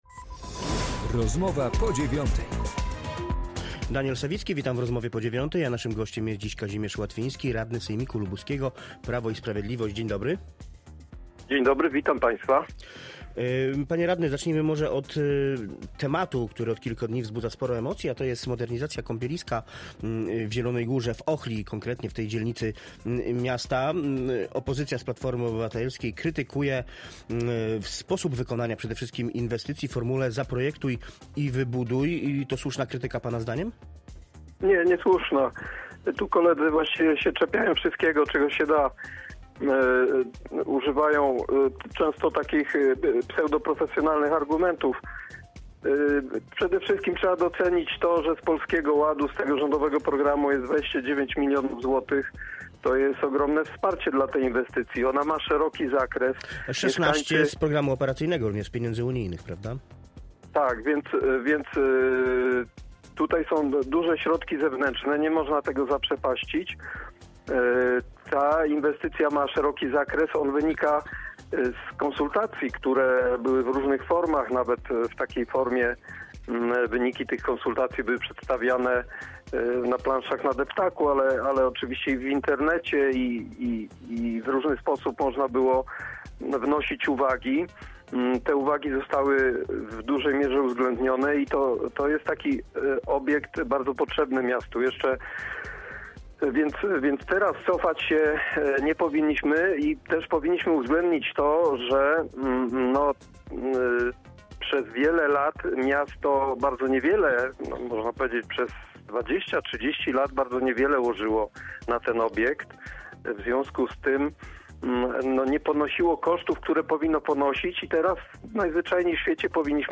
Z radnym sejmiku rozmawia